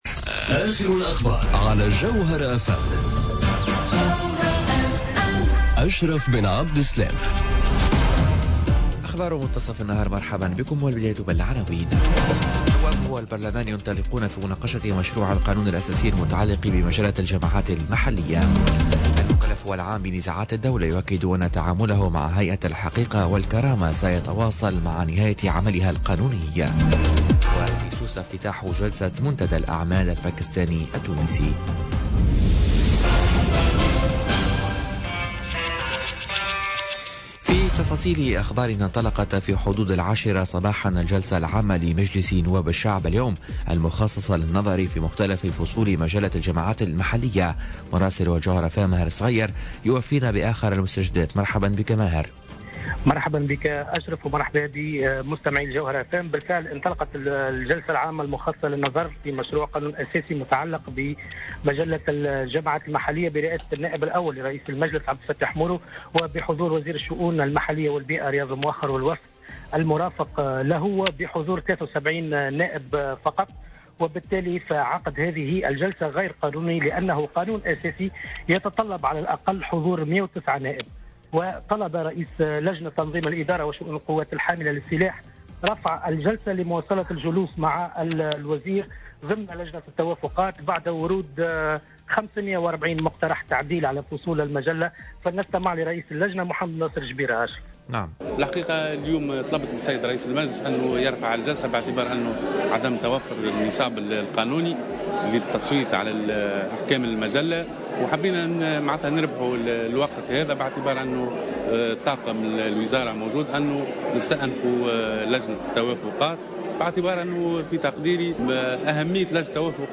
نشرة أخبار منتصف النهار ليوم الخميس 29 مارس 2018